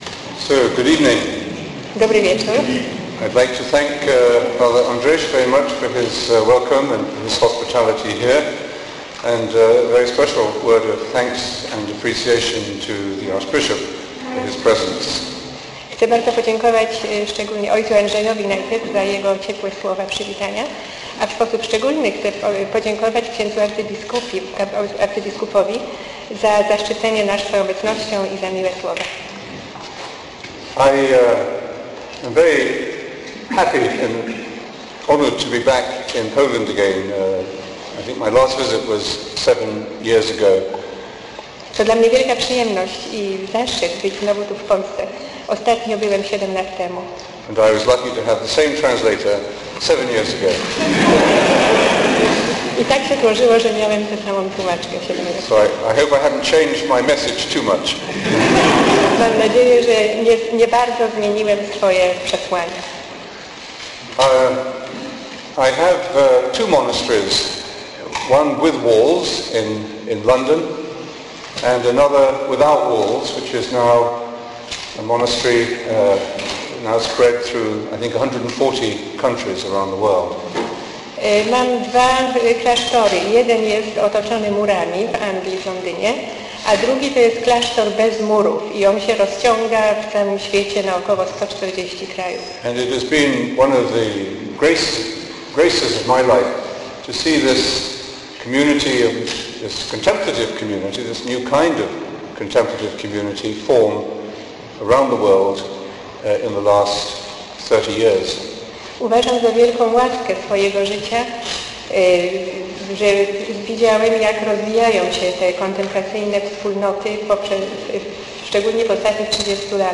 Pliki mp3 z nagrań konferencji